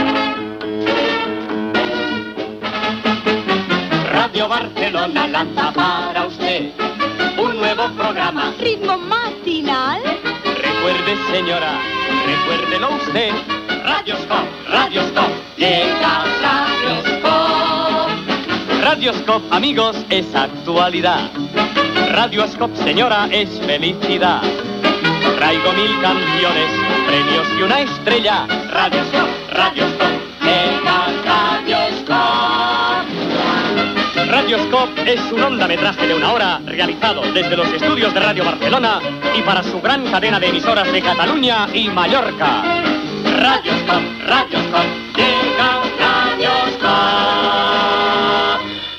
Careta d'inici del programa.